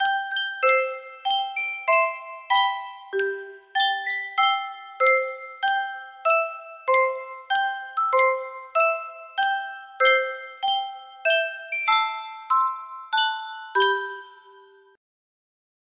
Sankyo Music Box - The Star-Spangled Banner FB
Full range 60